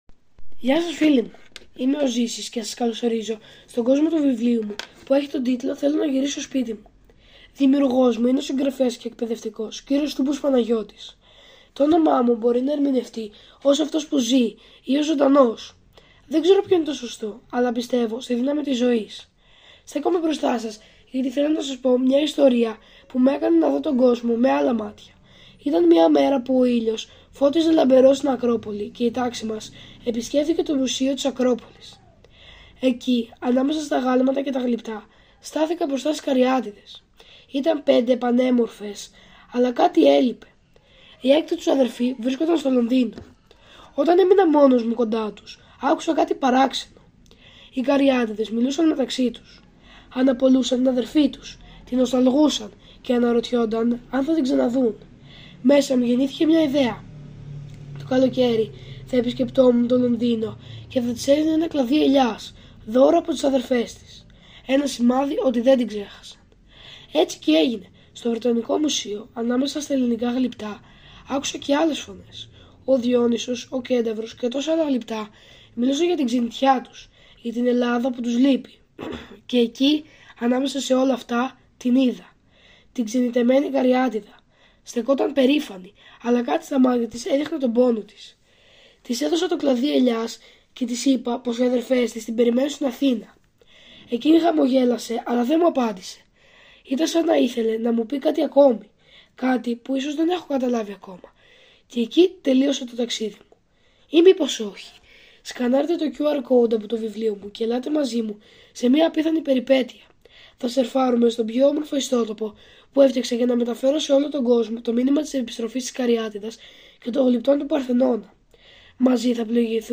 When Zisis finds his voice, it overflows with liveliness and sensitivity, conveying the message of nostalgia, return, and unity in a unique way – a message that touches the hearts of both young and old. His story highlights the power of cultural heritage and the longing for the reunification of the Parthenon Sculptures, while also inspiring the reader to see art not merely as exhibits adorning a museum, but as living entities with emotions, desires, memories, and stories.